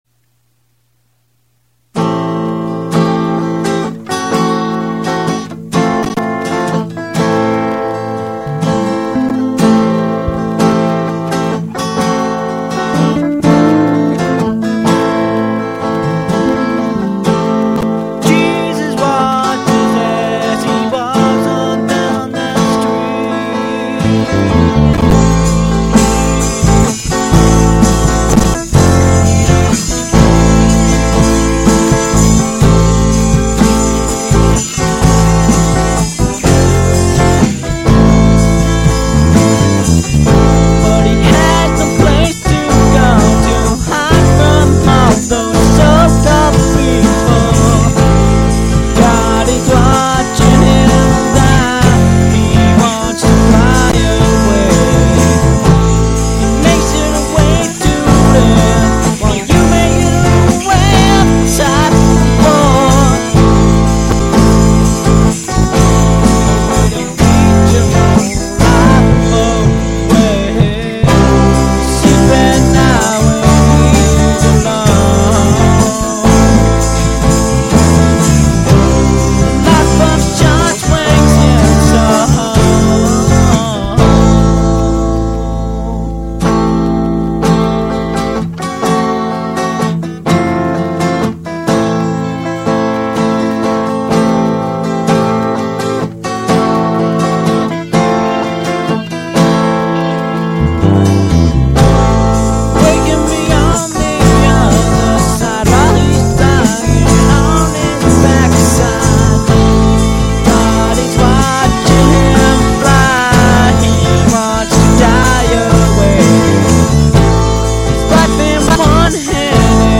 Bass
Guitar
Vocals